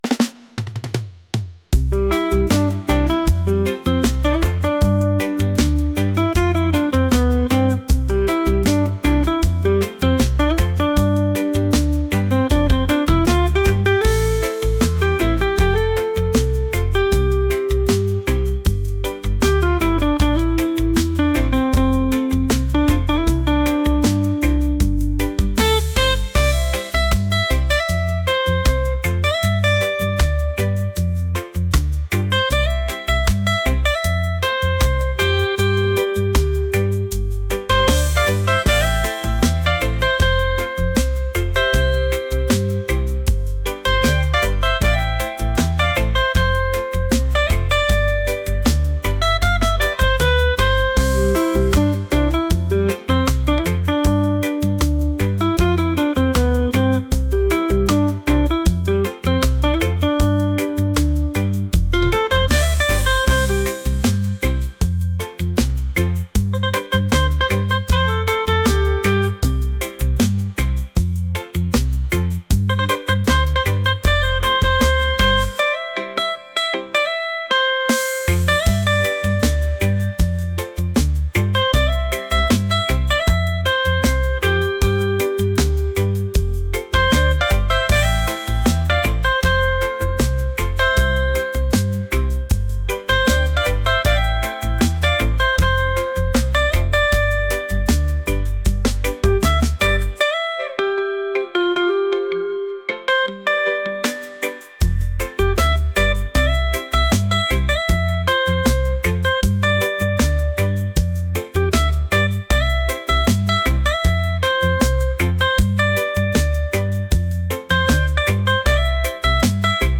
romantic | reggae